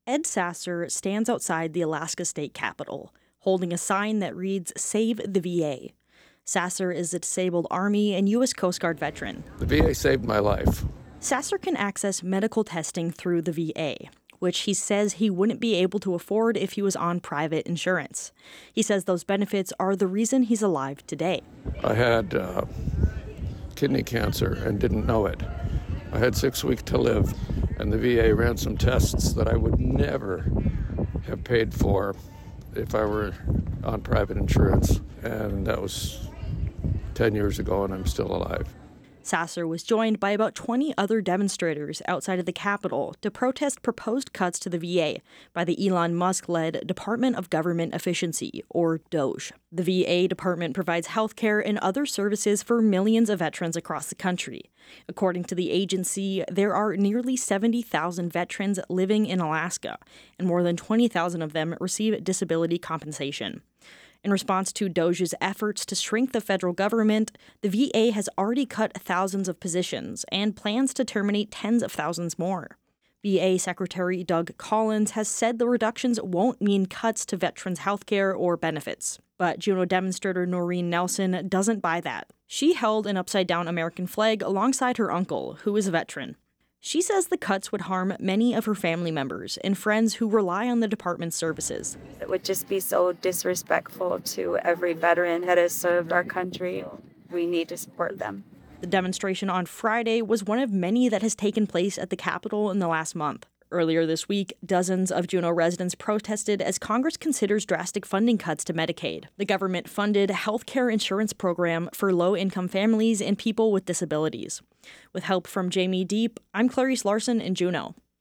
Juneau residents and veterans rallied outside the Alaska State Capitol on Friday, in response to the Trump Administration’s plans to eliminate tens of thousands of jobs in the U.S. Department of Veterans Affairs.
14VAdemonstration.wav